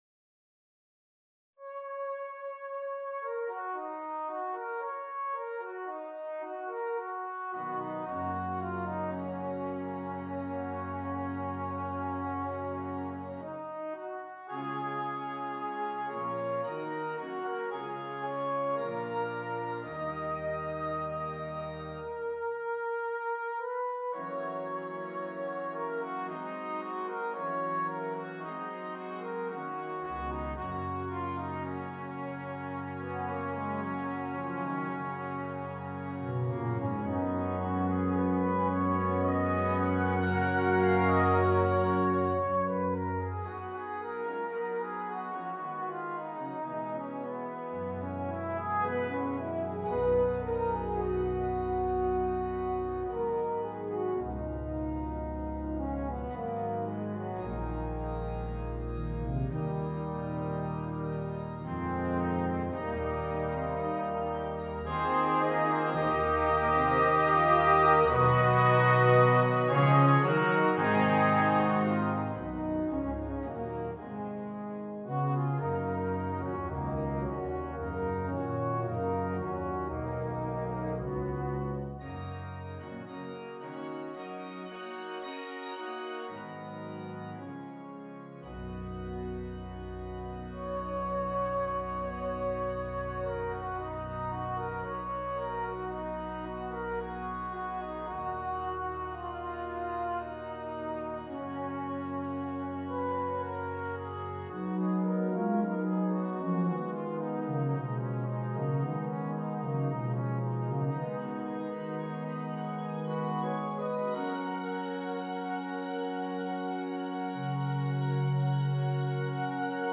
Brass Quintet and Organ
with its impressionistic and progressive harmonies
This is an arrangement for brass quintet and organ.